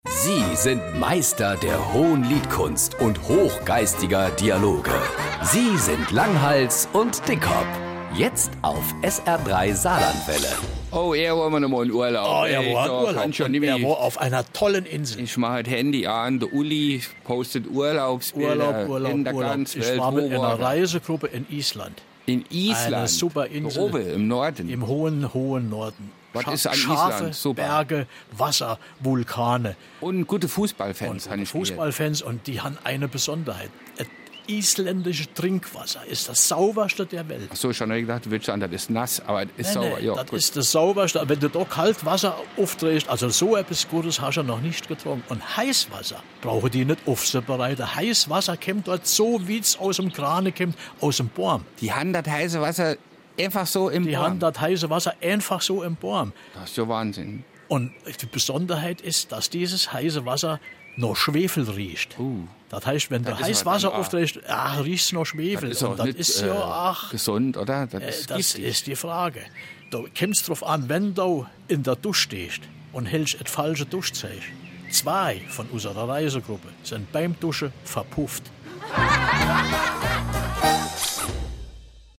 Komödie
Die sprachlichen Botschafter Marpingens in der weiten saarländischen Welt. Philosophisch, vorder-, tief- und hintergründig lassen sie uns teilhaben an ihren mikrokosmischen An- und Einsichten.